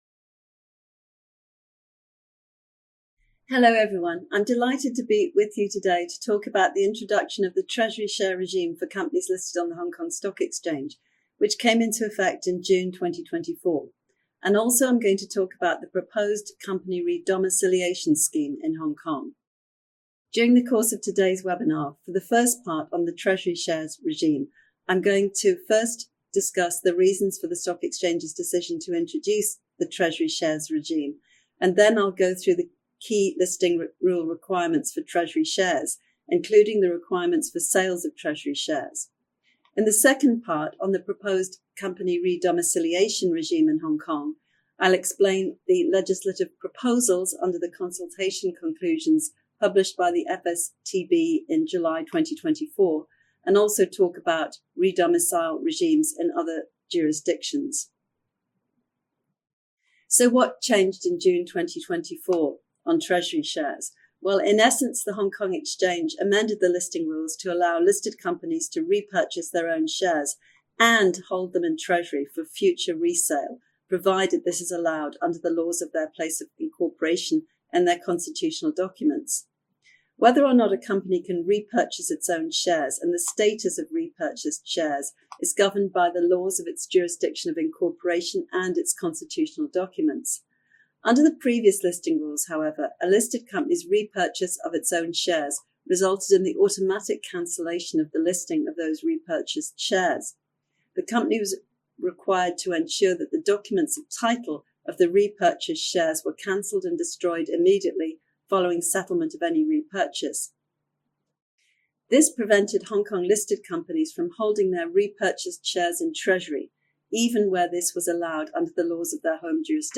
The webinar introduces significant changes related to the treasury share regime and the proposed company re-domiciliation scheme in Hong Kong, focusing first on the treasury share regime that began in June 2024.